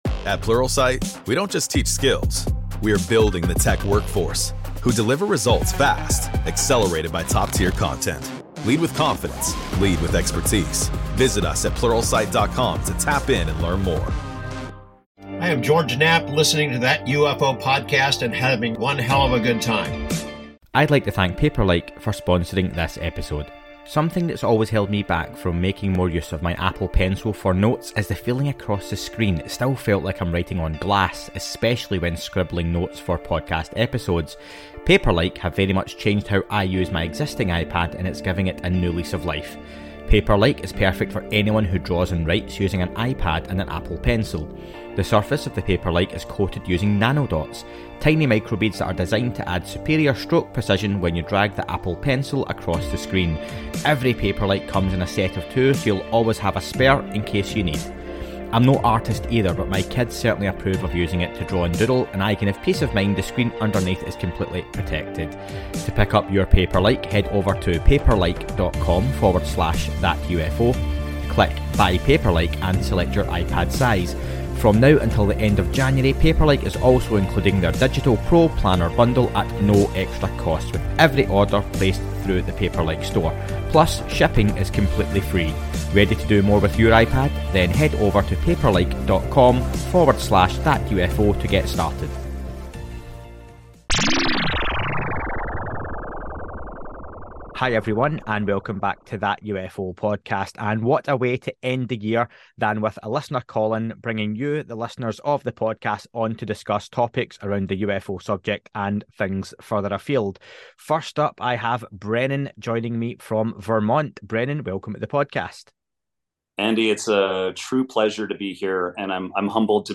Listener call-in December 2022, part 2